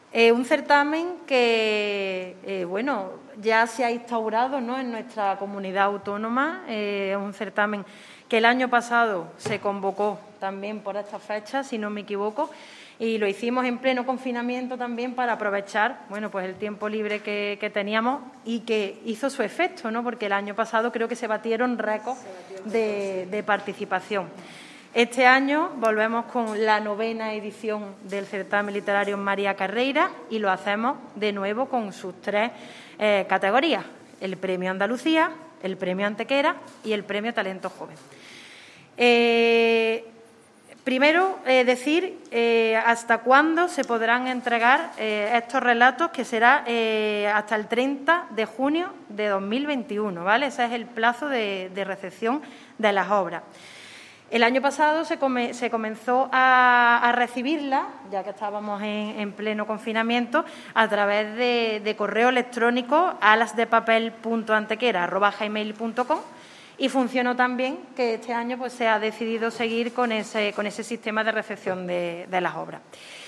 ENLACE A VÍDEO DE LA RUEDA DE PRENSA EN YOUTUBE
Cortes de voz